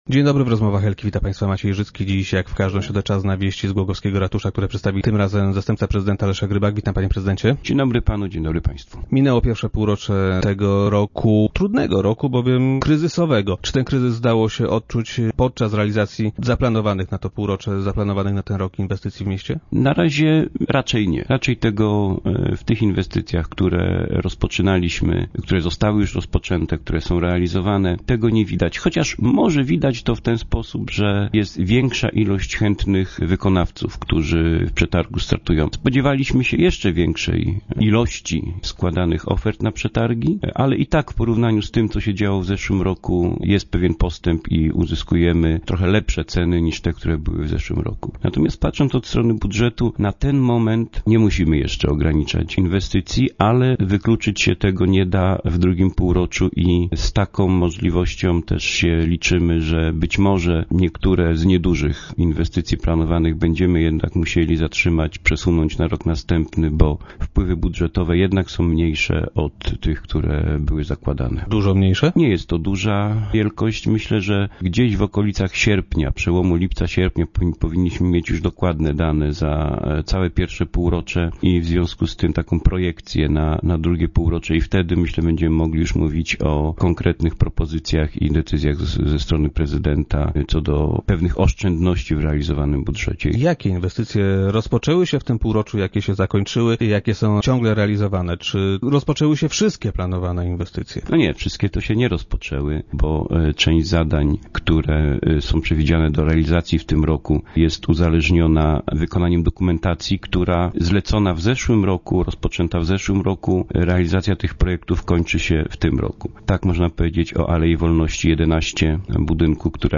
Jak powiedział Leszek Rybak, zastępca prezydenta Głogowa, który był gościem dzisiejszych Rozmów Elki, w pierwszym półroczu tego roku, kryzys nie wpłynął na miejskie inwestycje.
Uzyskujemy też w przetargach lepsze ceny - powiedział wiceprezydent Rybak w radiowym studio.